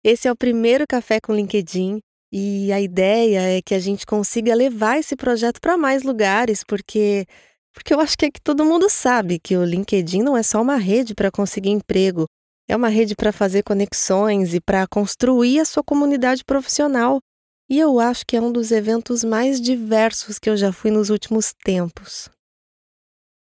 Sprechprobe: Werbung (Muttersprache):
My voice style is natural and conversational, with a neutral accent. My voice is very warm, versatile, conveys credibility, in addition to being jovial, expressive and extremely professional.